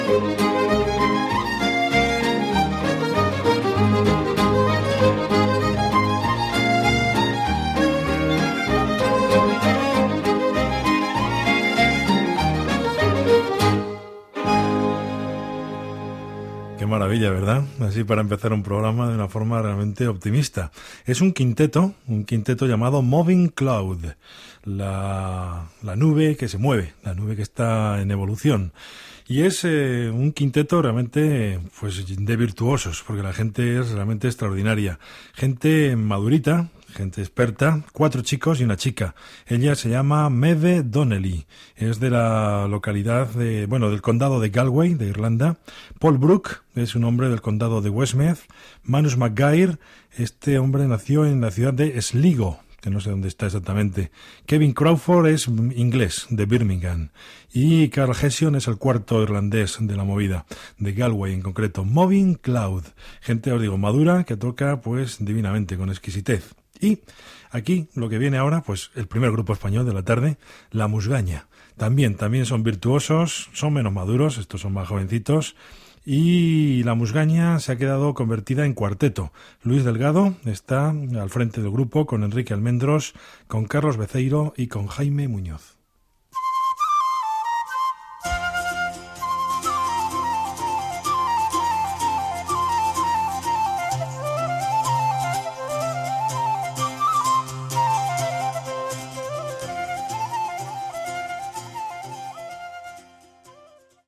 Tema musical, comentari del tema escoltat, presentació d'un nou tema
FM